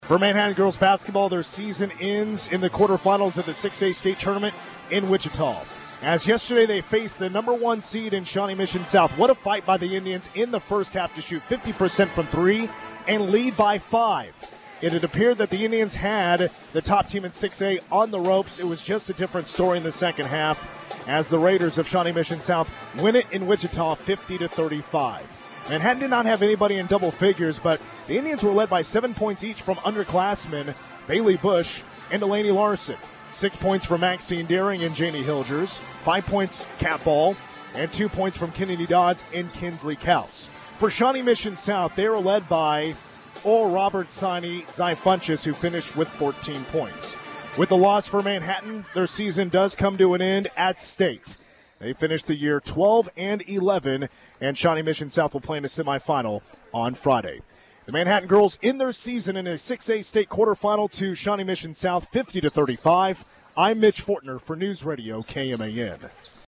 Recap